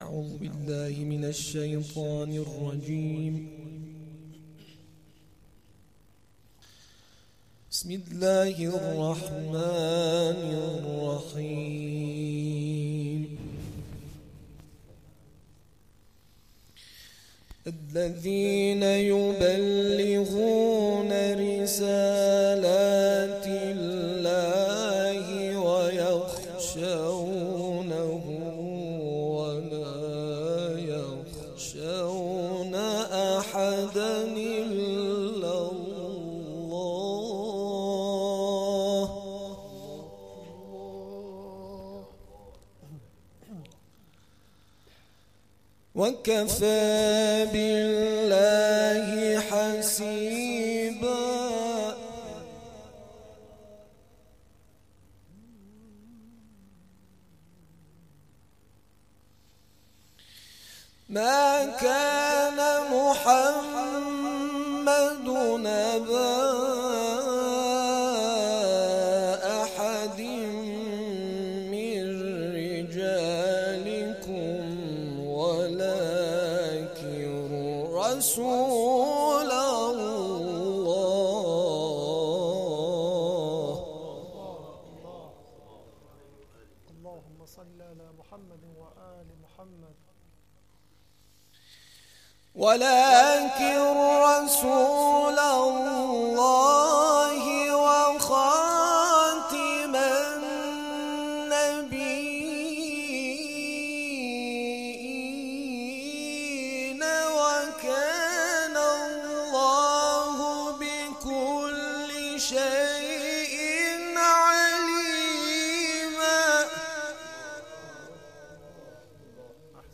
گروه جلسات و محافل: دومین جلسه مجمع قاریان محله سیزده آبان شهر ری، در مسجد حضرت ابوالفضل(ع) برگزار شد.